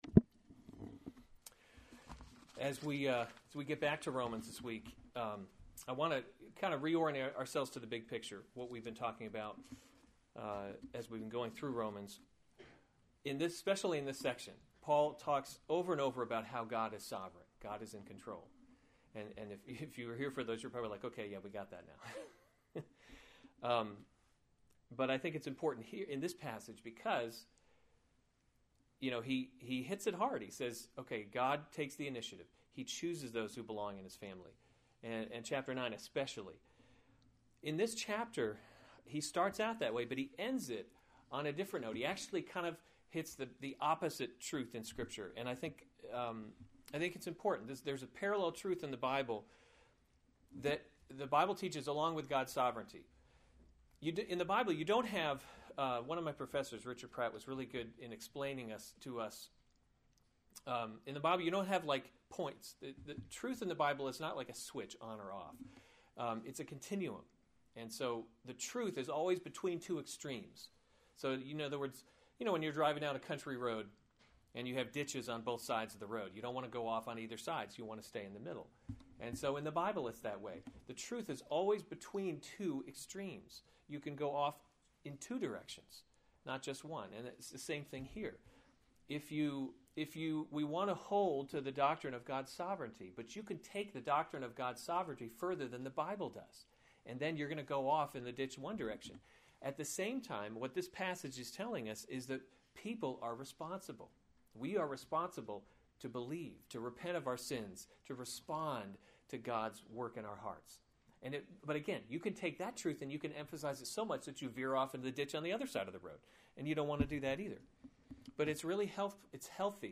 January 3, 2015 Romans – God’s Glory in Salvation series Weekly Sunday Service Save/Download this sermon Romans 10:16-21 Other sermons from Romans 16 But they have not all obeyed the gospel.